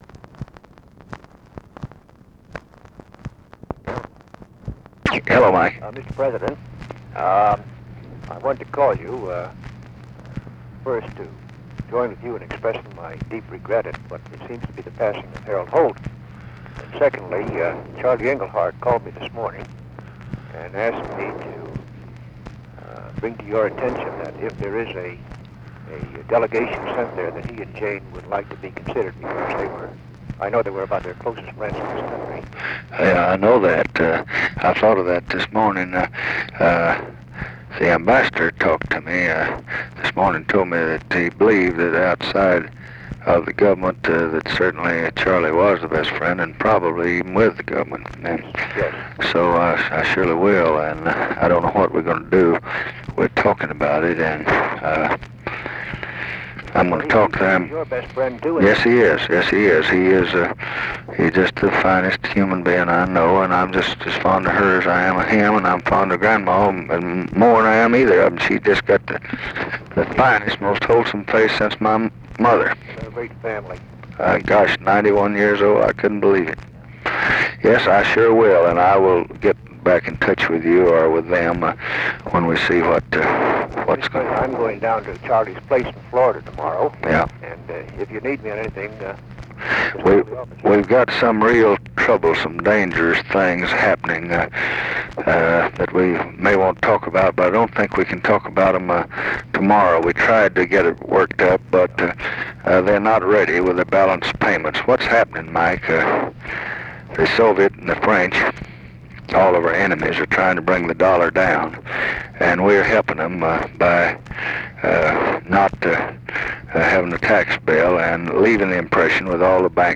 Conversation with MIKE MANSFIELD and OFFICE CONVERSATION, December 17, 1967
Secret White House Tapes